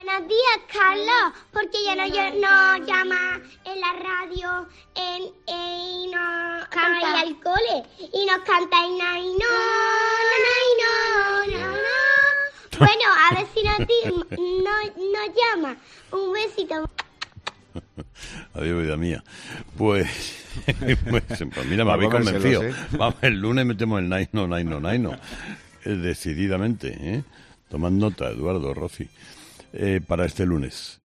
Esta vez han sido protagonistas unos niños que, ni cortos ni perezosos, le han solicitado a Carlos Herrera que incluya un cambio fundamental en el programa: "Buenos días, Carlos. ¿Por qué ya no llamas en la radio para ir al cole? Y nos cantas el 'nainooo nainoo naaa'. Bueno, a ver si nos llamas. Un besito".